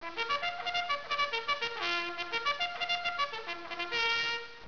bugle2.wav